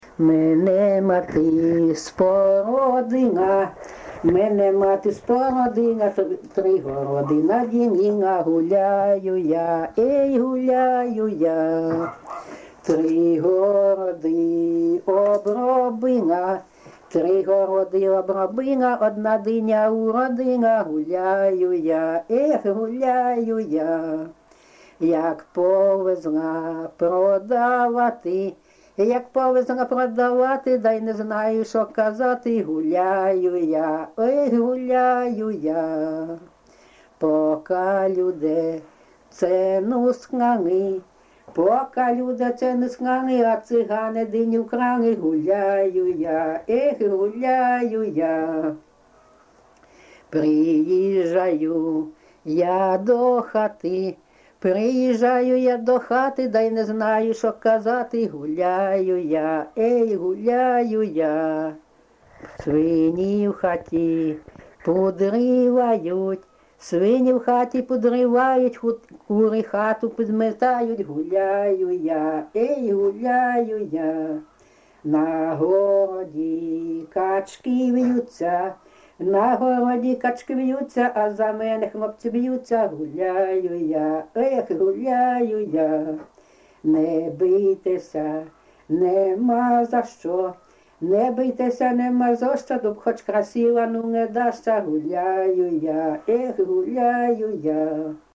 ЖанрЖартівливі
Місце записус. Курахівка, Покровський район, Донецька обл., Україна, Слобожанщина